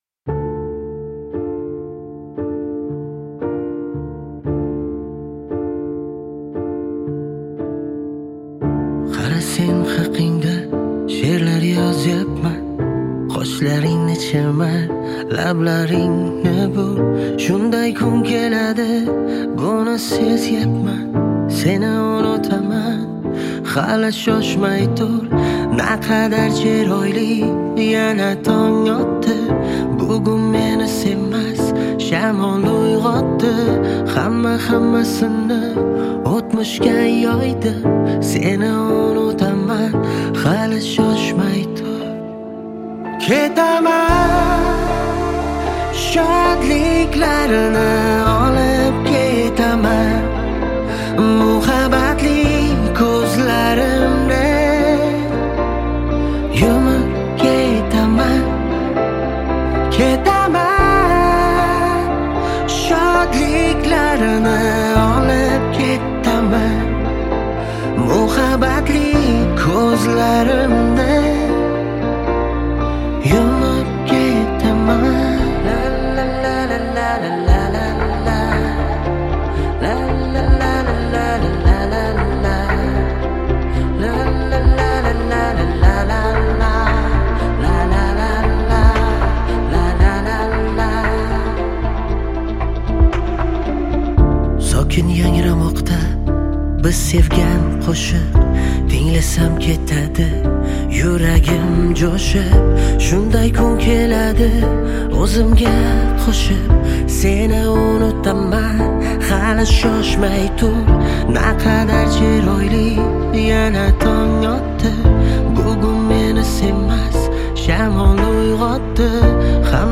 • Жанр:  Новые песни / Узбекиский новинки